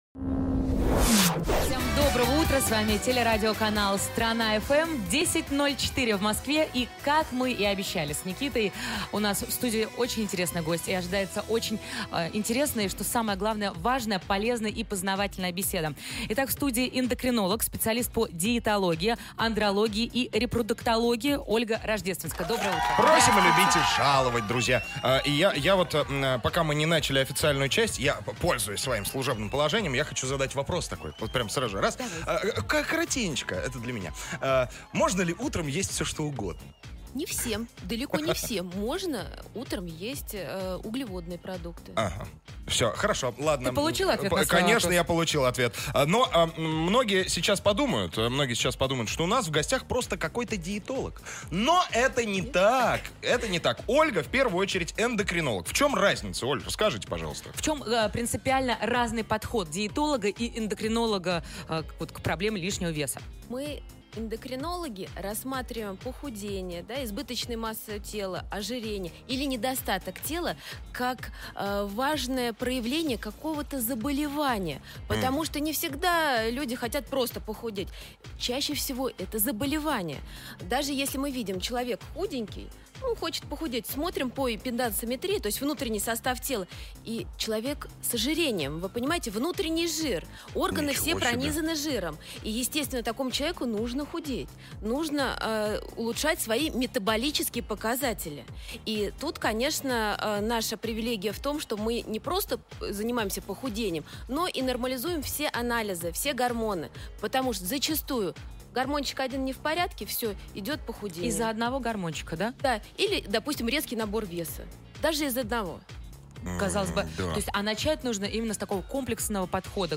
Источник: Радио Страна ФМ